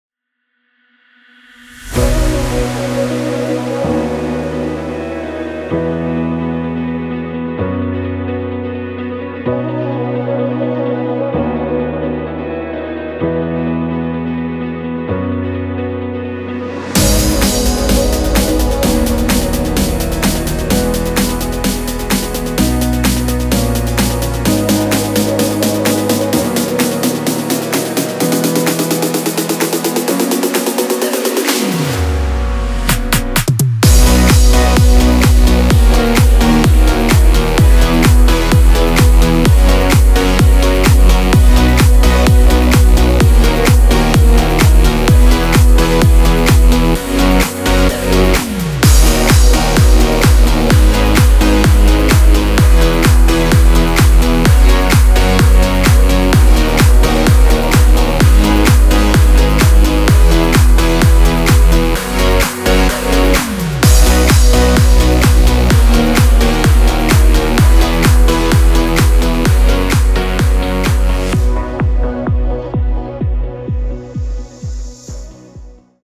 Popschlagersong im aktuellen Style.
Hier kannst du kurz ins Playback reinhören.
BPM – 128
Tonart – B-major